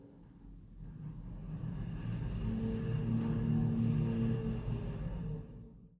Lift moving 4.mp3